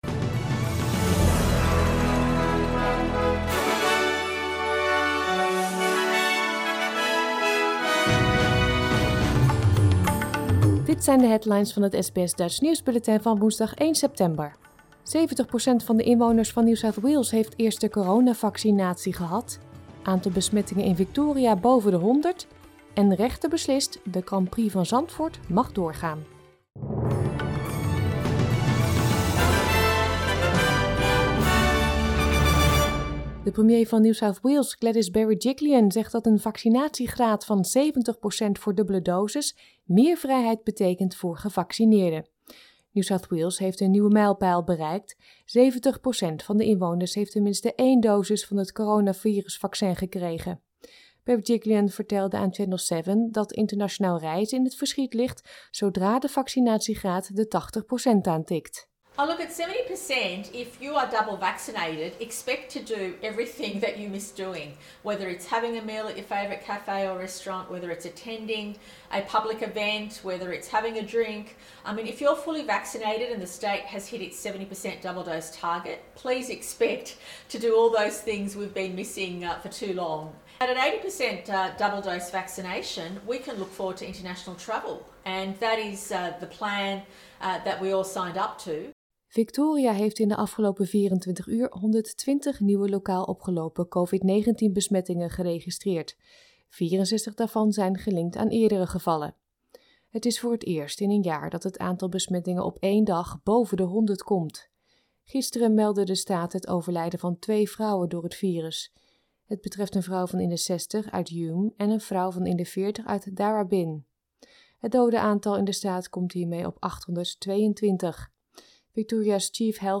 Nederlands/Australisch SBS Dutch nieuwsbulletin van woensdag 1 september 2021